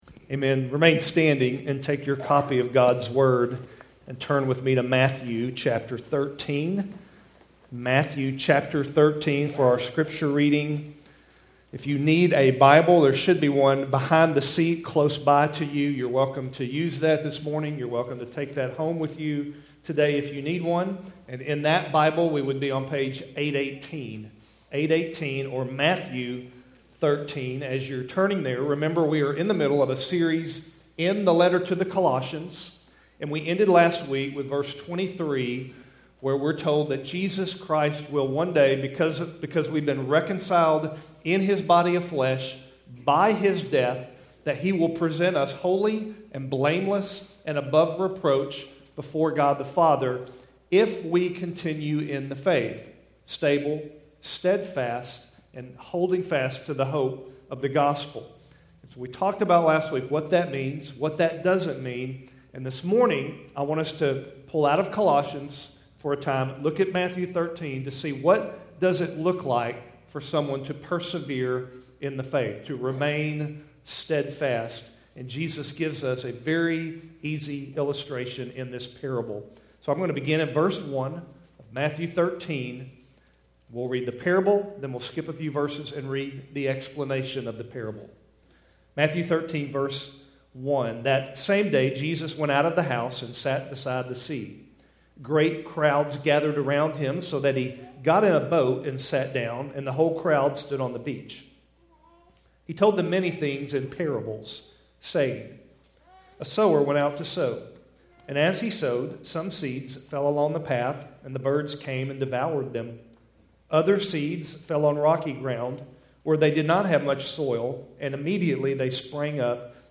Keltys Worship Service, December 13, 2020